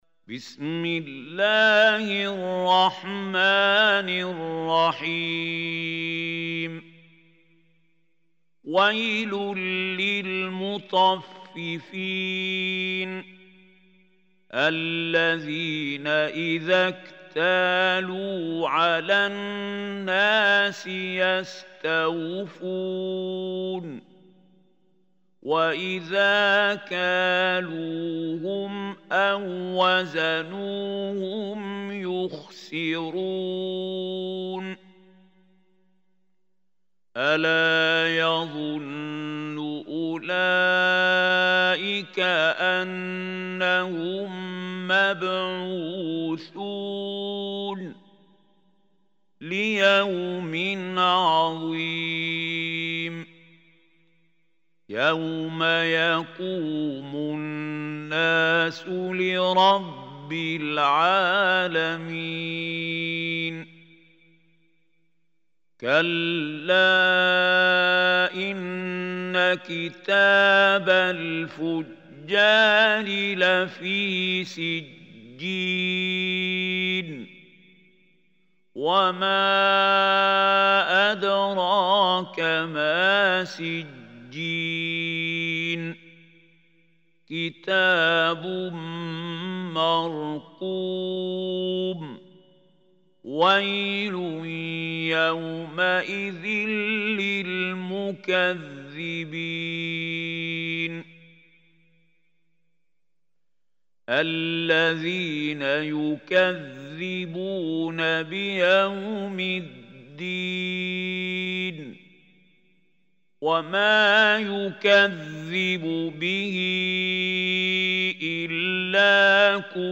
Surah Mutaffifin MP3 Recitation by Mahmoud Hussary
Surah Mutaffifin is 83 surah of Holy Quran. Listen or play online mp3 tilawat / recitation in the beautiful voice of Mahmoud Khalil Hussary.